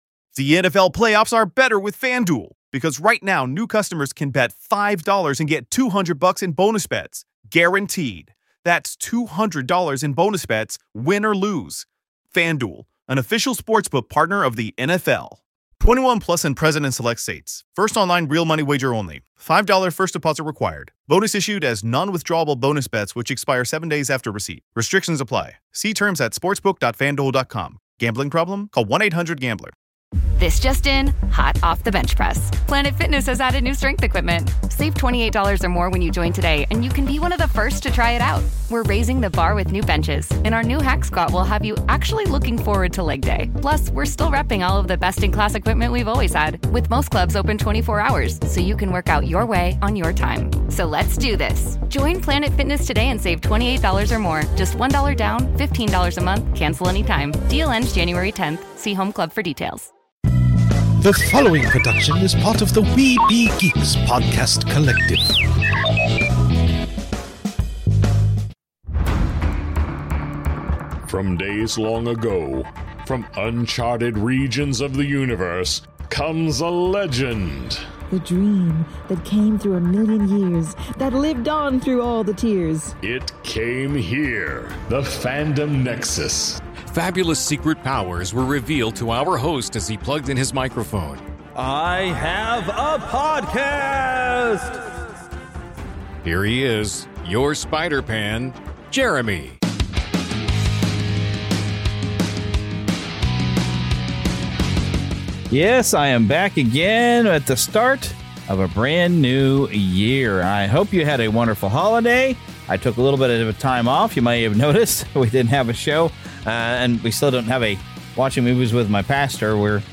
You may have noticed some ads as part of the show this week.